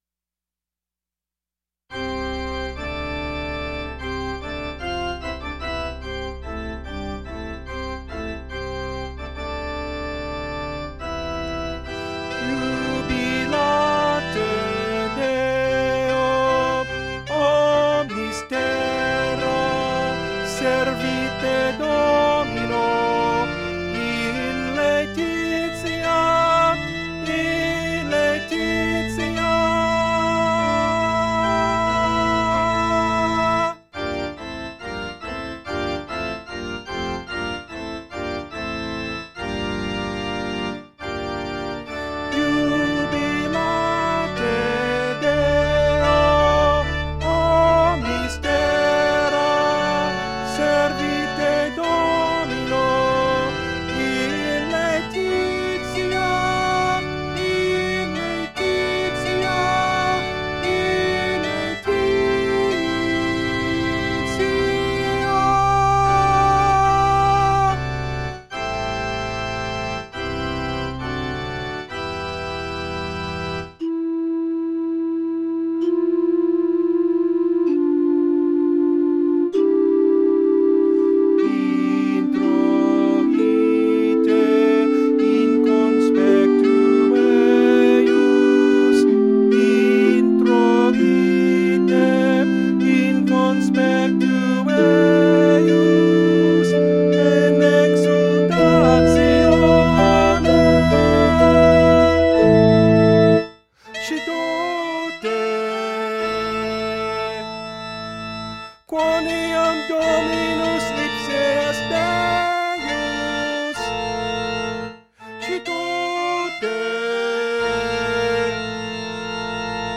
Soprano   Instrumental | Downloadable